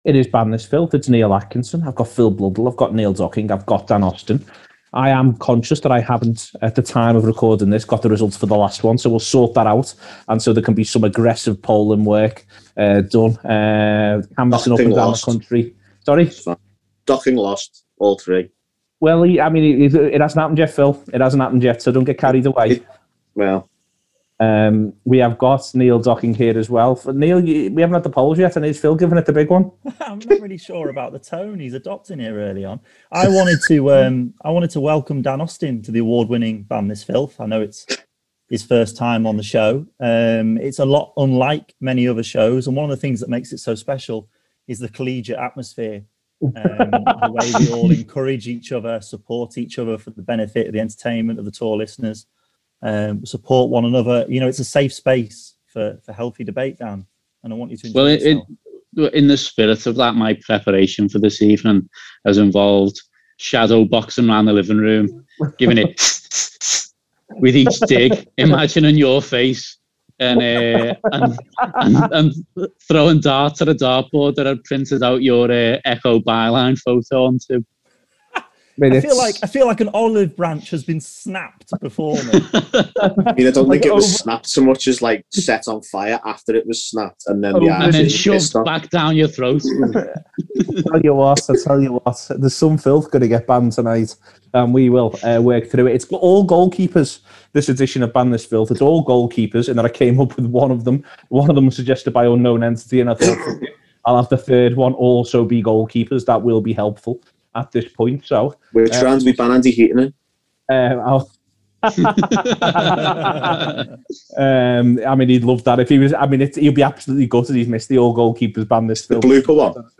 the panel debate what they’d ban from the following categories: